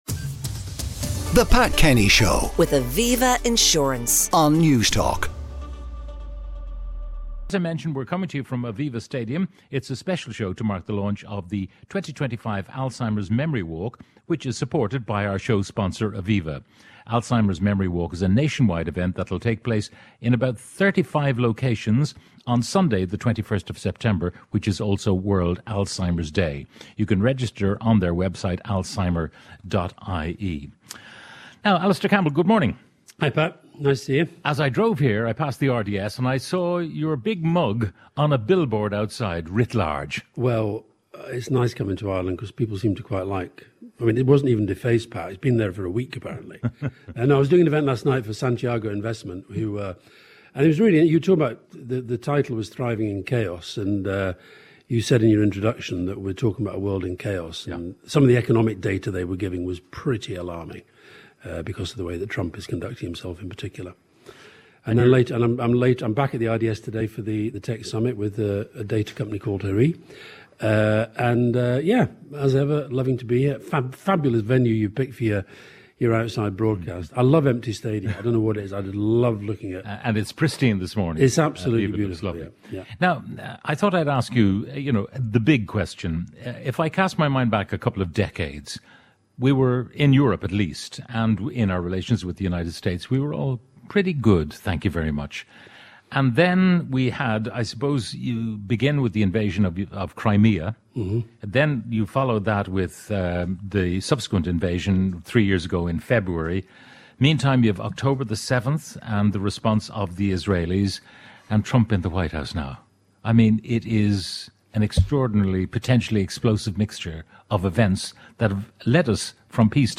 Our show today was live from AVIVA stadium, t…